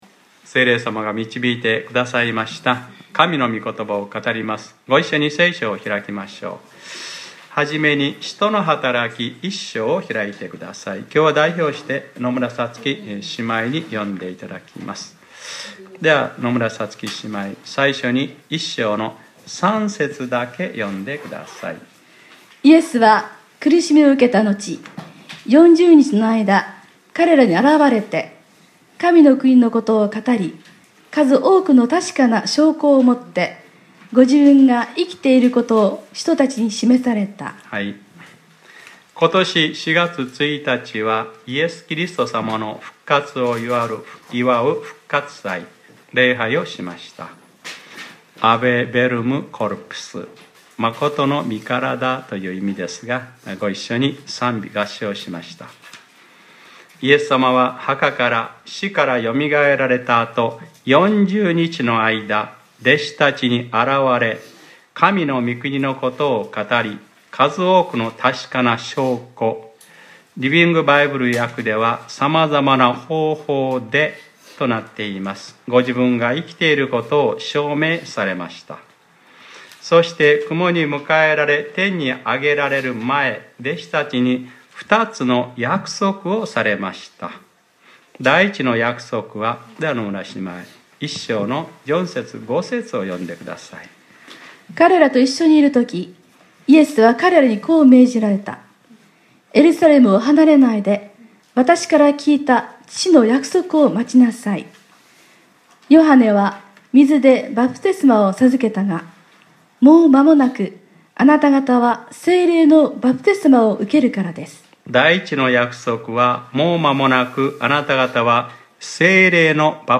2018年05月13日（日）礼拝説教『ペンテコステ：聖霊の人格と働き』